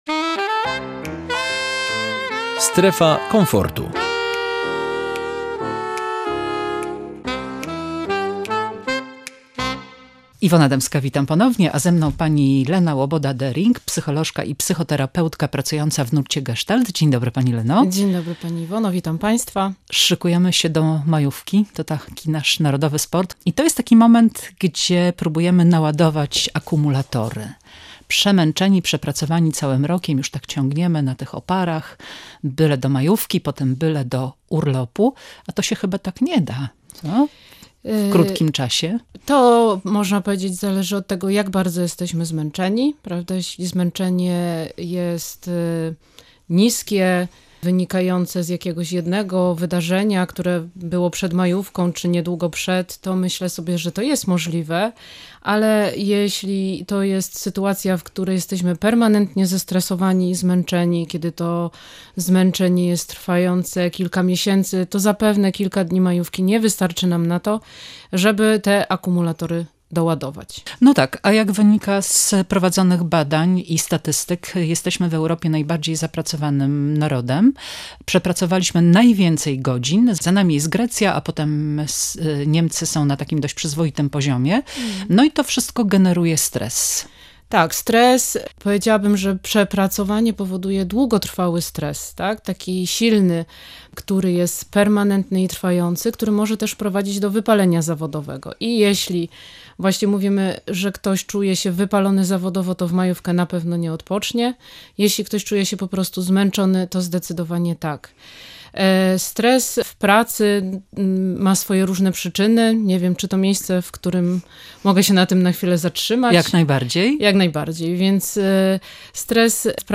psychoterapeutą i psychologiem specjalizująca się w psychoterapii Gestalt rozmawiała